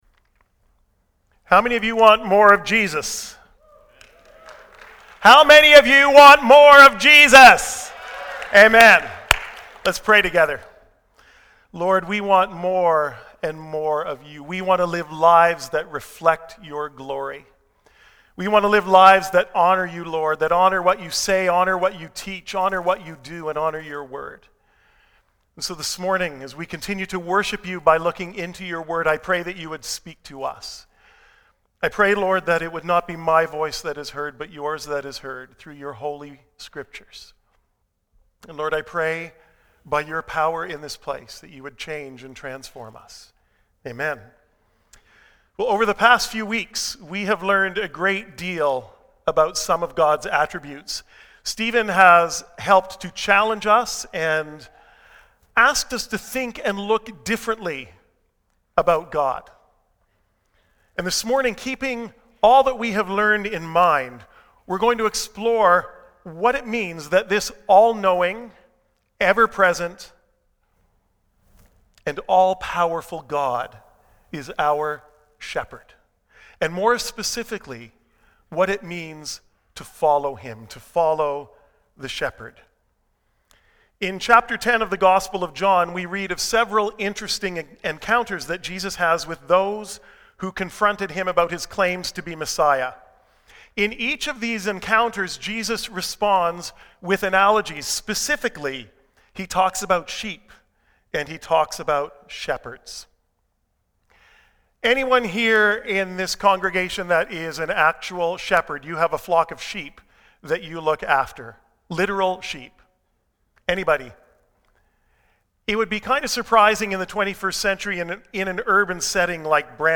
Sermons | Bramalea Baptist Church